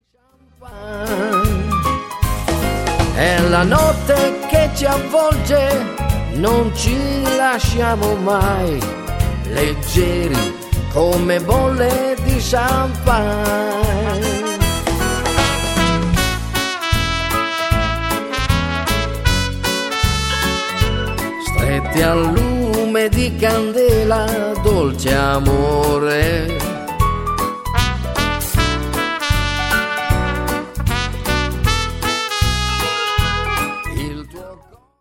FOX - TROT  (02.30)